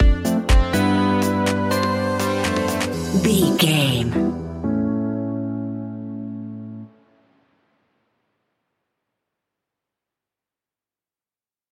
Aeolian/Minor
uplifting
energetic
bouncy
synthesiser
drum machine
electric piano
funky house
electro
groovy
instrumentals